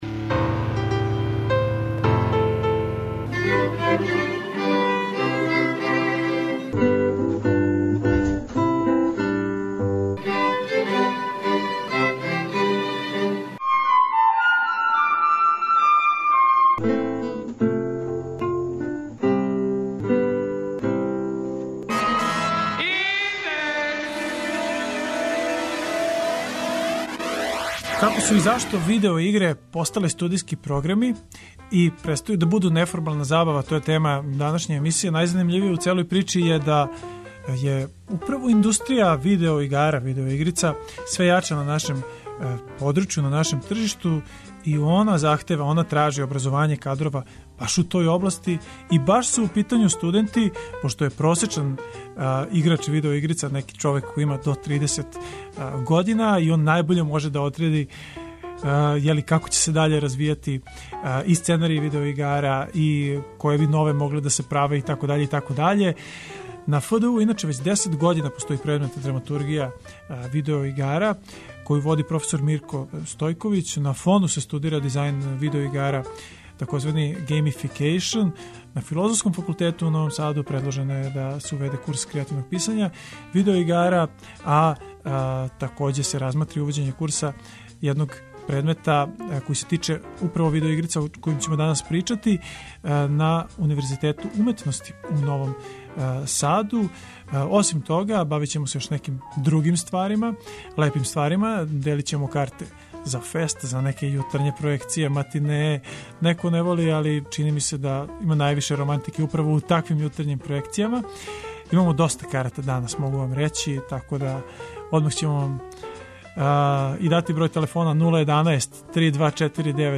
О овоме разговарамо и са студентима који похађају праксу ‘'стварања'' видео игара у компанијама.
преузми : 18.09 MB Индекс Autor: Београд 202 ''Индекс'' је динамична студентска емисија коју реализују најмлађи новинари Двестадвојке.